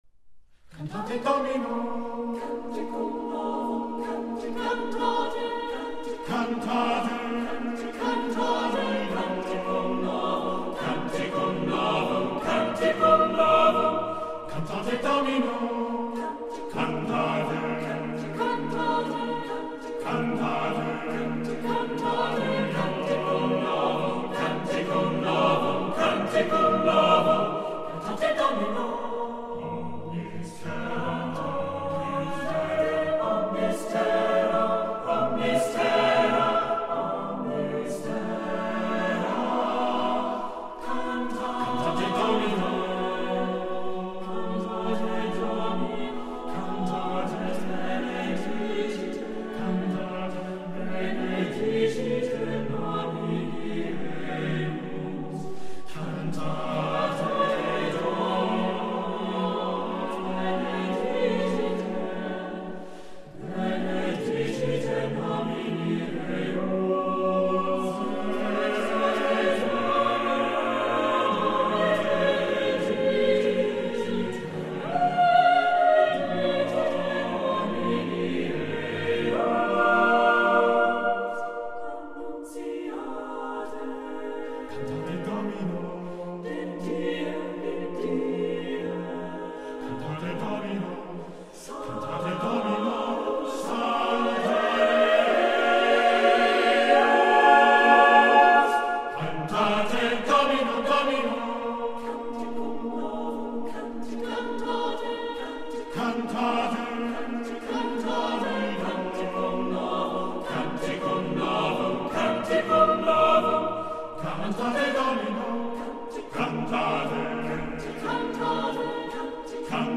A colloquio con il compositore